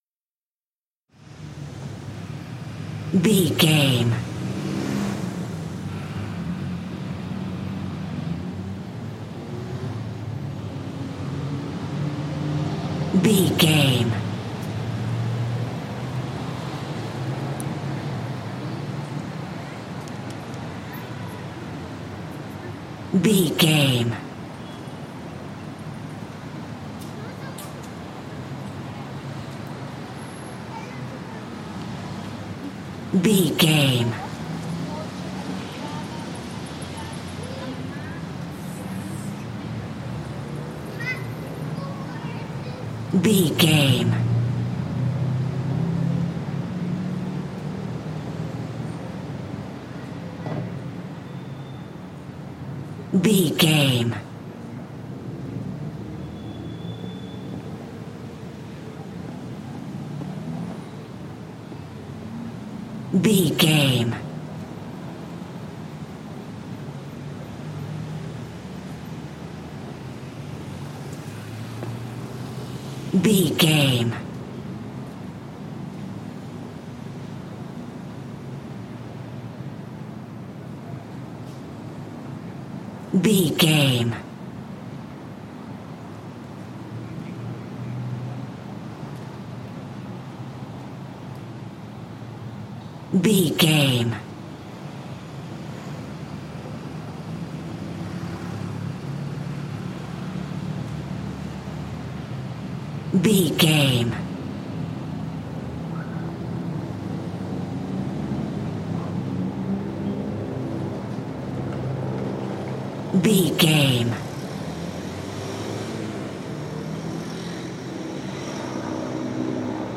City calm street 21
Sound Effects
SEAMLESS LOOPING?
urban
ambience